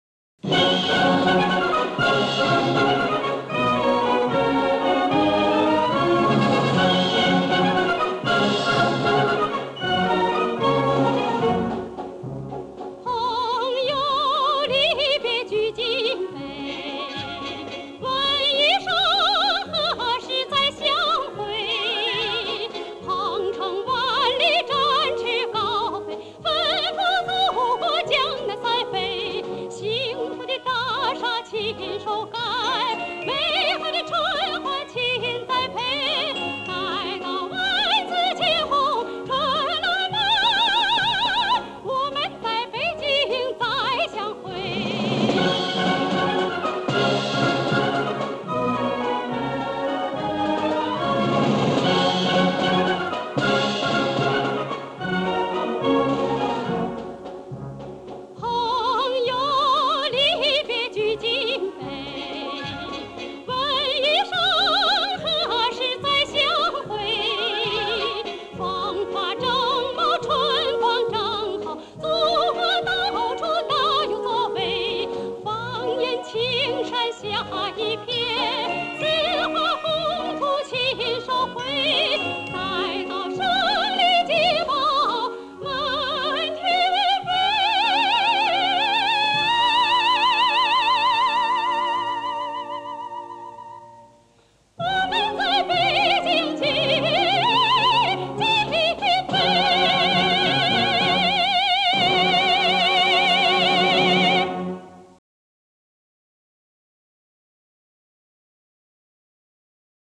她这个唱法那时候就叫西洋唱法。
典型的主旋律的三拍子套路